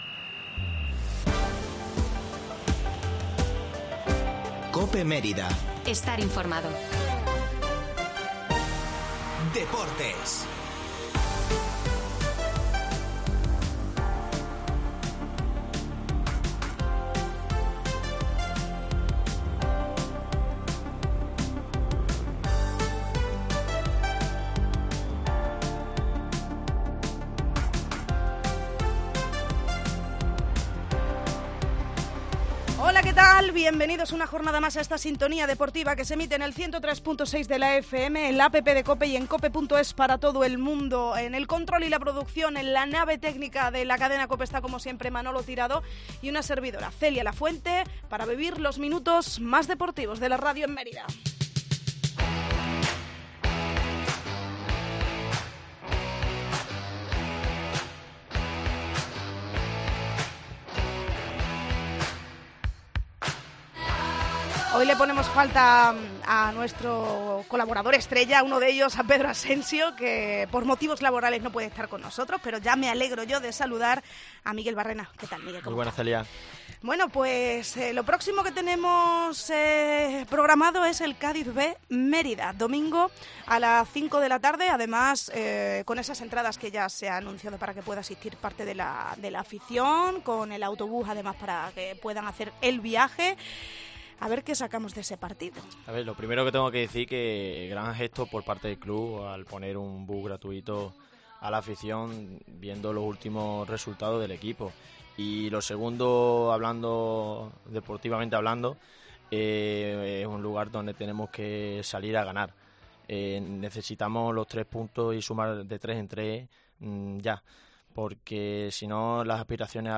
La tertulia del Mérida en COPE